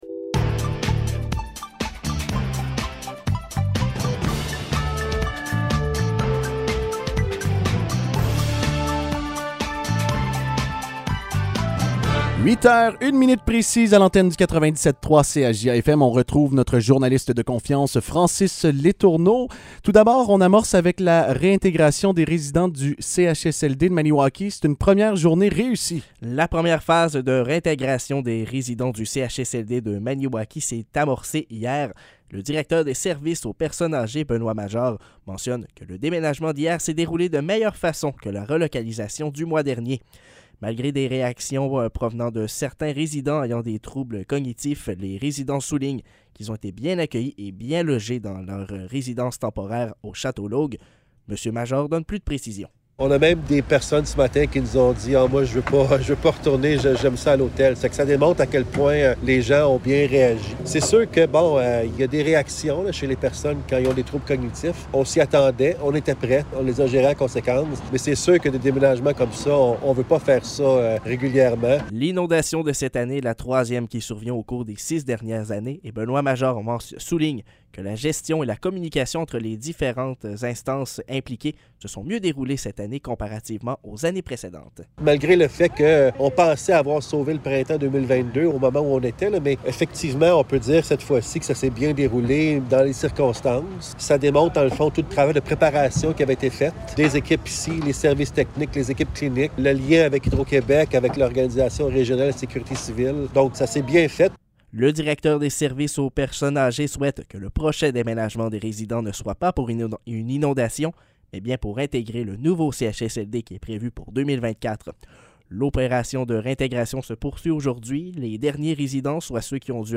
Nouvelles locales - 22 juin 2022 - 8 h